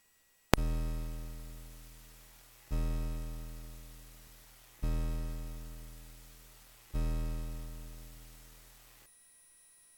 The combination of low frequency and the triangle wave makes very evident the presence of aliasing in some of them.
test-fpgasid-6581-dac-crunch.mp3